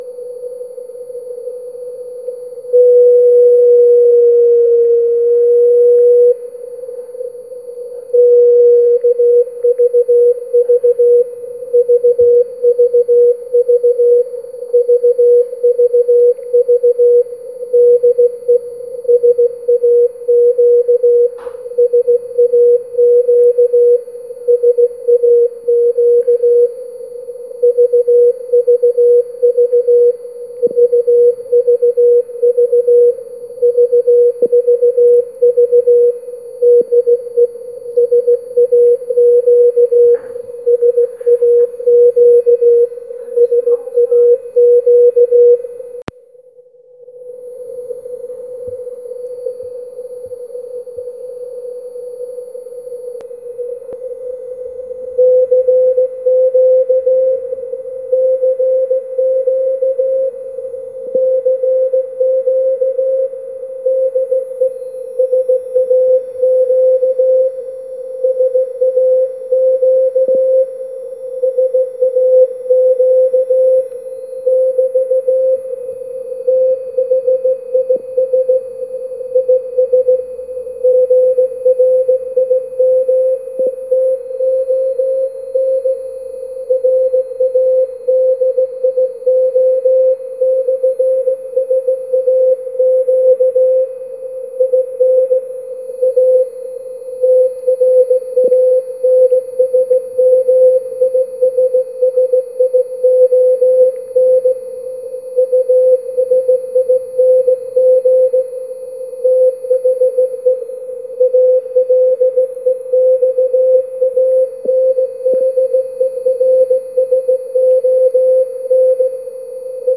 Die Signale an der Antenne R&S HE011 schwankten zwischen S8 und S9+10dB.